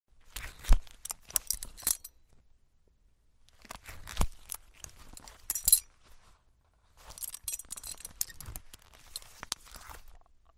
Tiếng chỉnh sửa, đeo khóa Thắt Lưng
Thể loại: Tiếng đồ vật
Description: Tổng hợp hiệu ứng tiếng chỉnh sửa, đeo khóa Thắt Lưng sột soạt, rọc rẹc, Belt Sound Effect...
tieng-chinh-sua-deo-khoa-that-lung-www_tiengdong_com.mp3